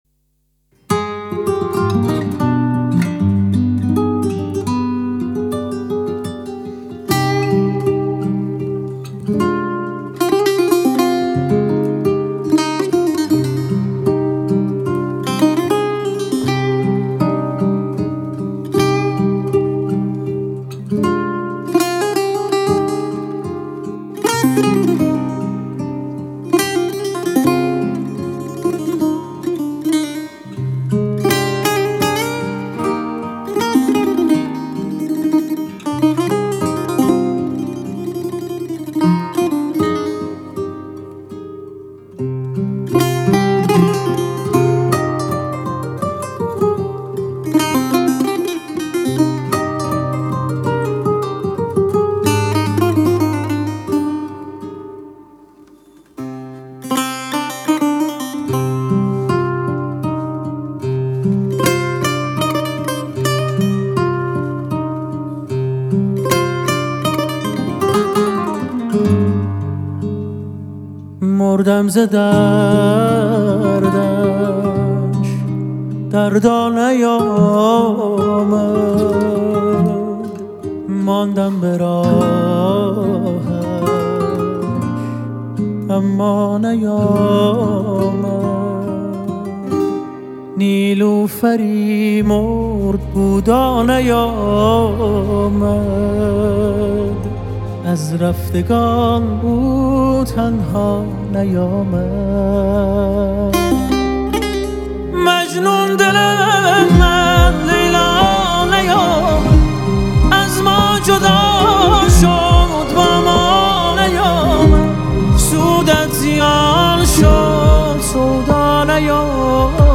همچنین نوازندگان نامداری در این قطعه هنرنمایی کرده‌اند.
سه‌تار
گیتار
ویلن آلتو
ویلنسل